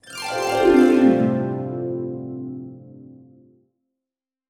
Magical Harp (6).wav